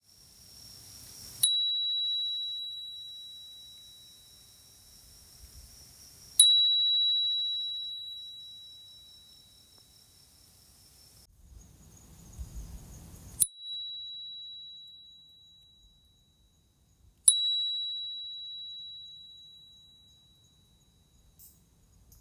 こちらが、誰もいない夜の公園でOi prima を4回鳴らしたのをiPhone録音した音源です。↓
アタックの瞬間、耳に障るように感じる刺激音は即時にミュートされます。
あとは3.8kHzあたりが基本周波数の音。およそ5秒程度でしょうか。優しくサステインしていきます。
倍音成分はギラギラしすぎないように抑えの効いた様子。
これが、ピーーーン　という透き通るような音の説明。